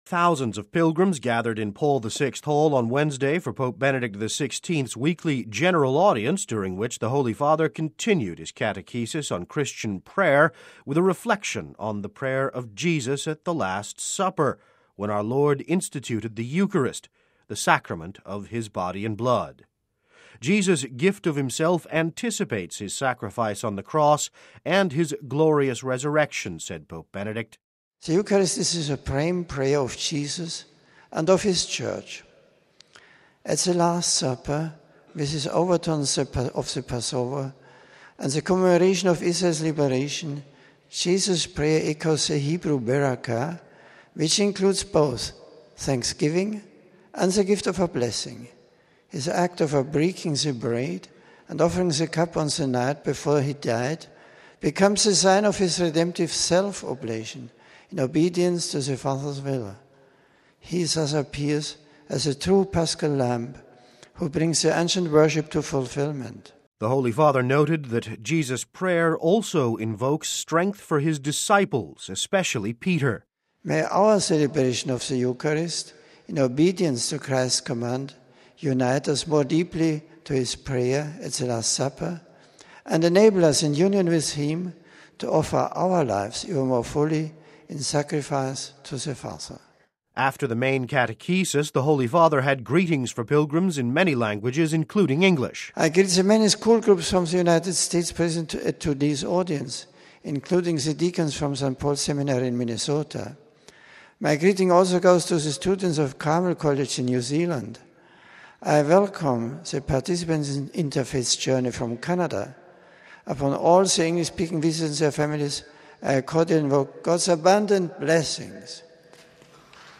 Thousands of pilgrims gathered in Paul VI Hall on Wednesday for Pope Benedict XVI’s weekly General Audience, during which the Holy Father continued his catechesis on Christian prayer, with a reflection on the prayer of Jesus at the Last Supper, when our Lord instituted the Eucharist, the sacrament of his Body and Blood.
After the main catechesis, the Holy Father had greetings for pilgrims in many languages, including English: